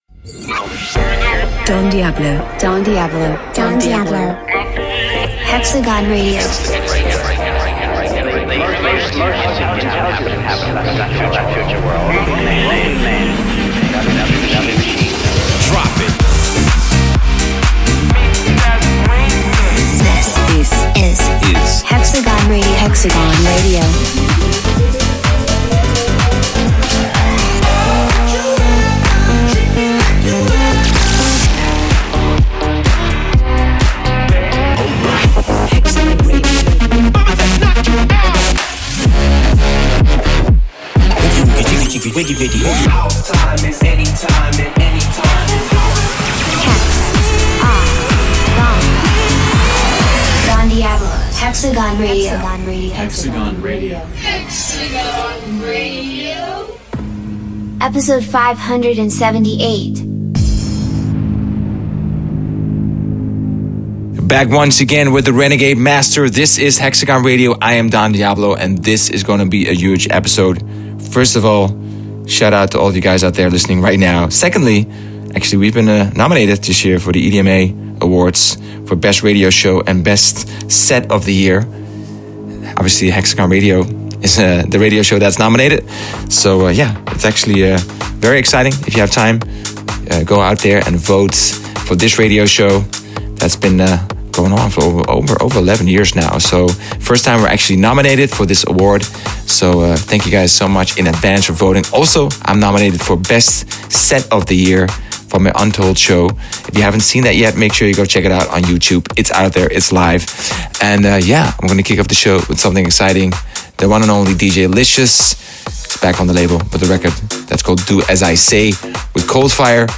Guest Mix: